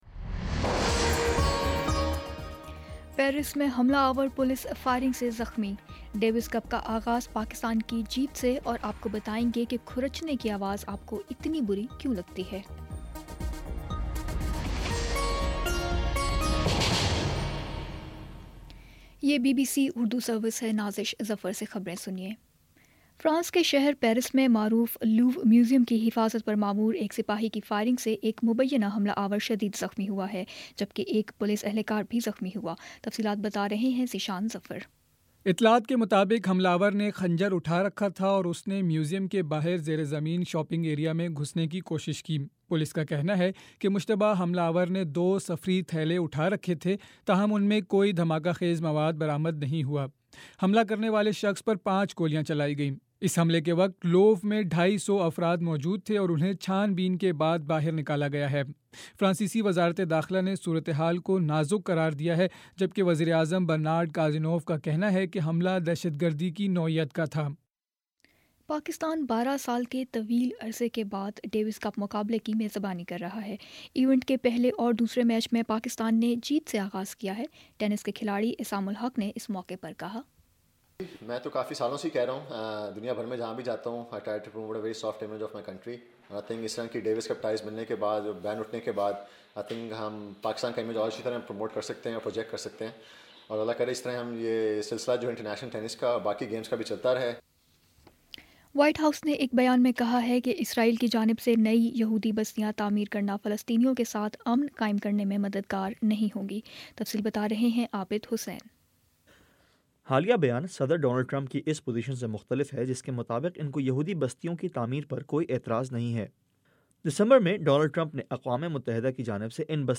فروری 03 : شام چھ بجے کا نیوز بُلیٹن